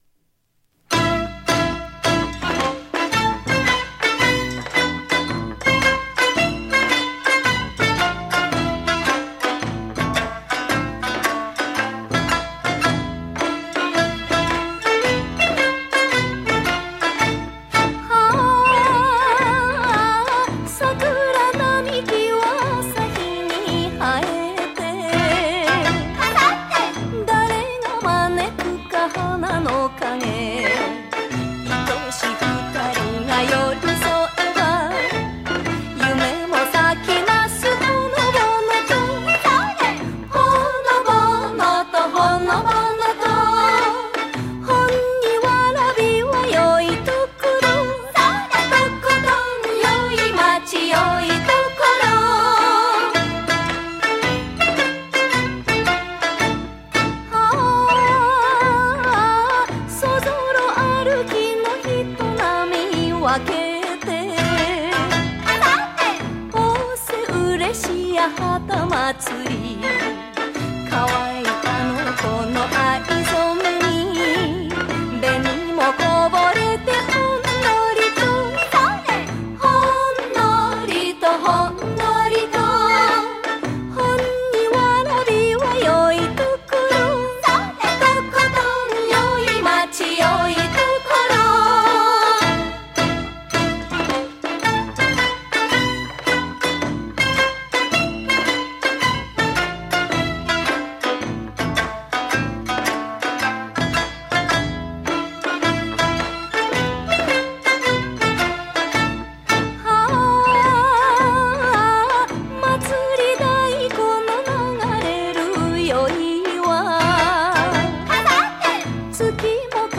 ※市制施行20周年字のレコードを音源としています。